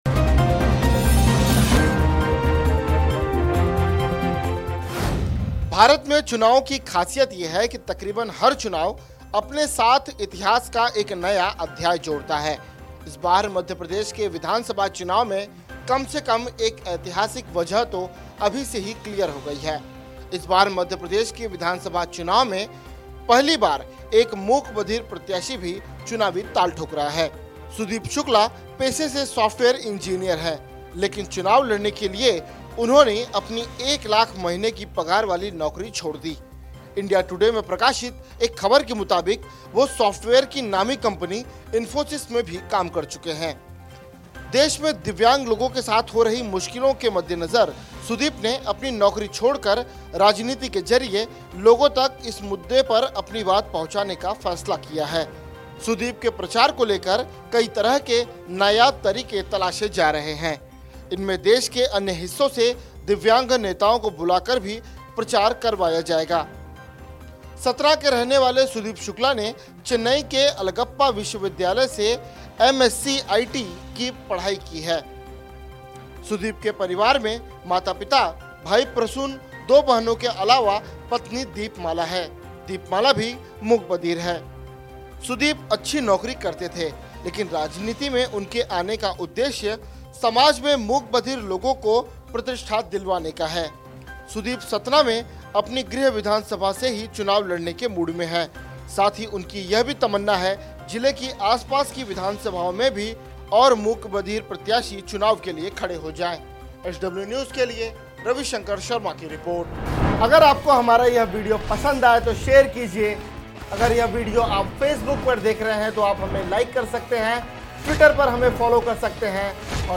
न्यूज़ रिपोर्ट - News Report Hindi / मध्यप्रदेश चुनाव में इतिहास, बीजेपी कांग्रेस को टक्कर देगा ये प्रत्याशी !